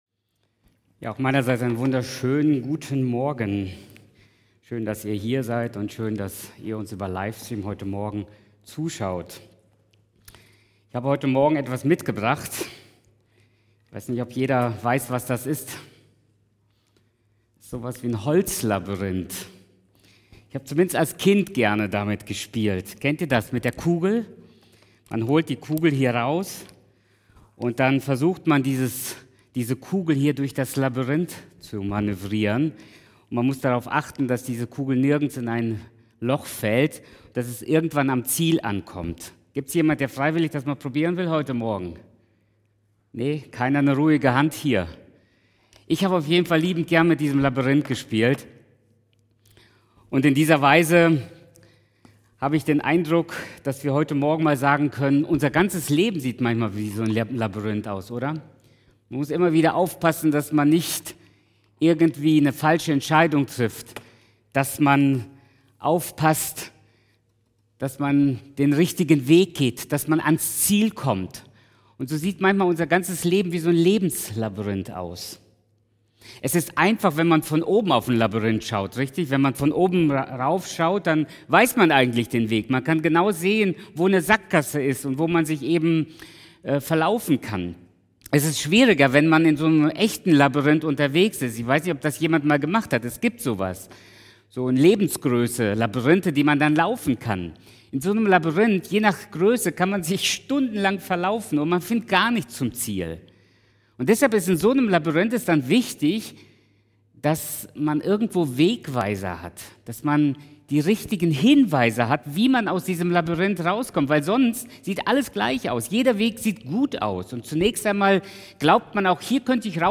Februar 2021 Predigt-Reihe: Ich bin...